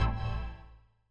ORG Chord Stab A.wav